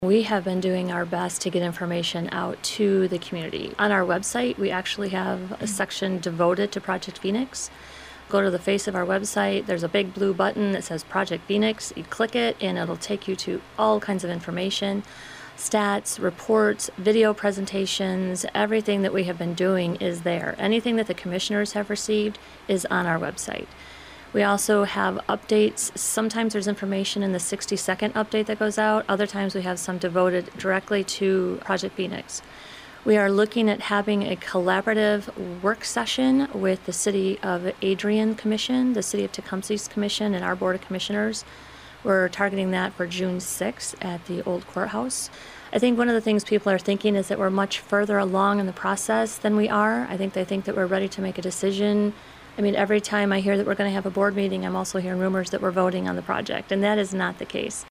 Lenawee County Administrator, Kim Murphy, was a guest on View Point this weekend, and addressed the concerns about transparency…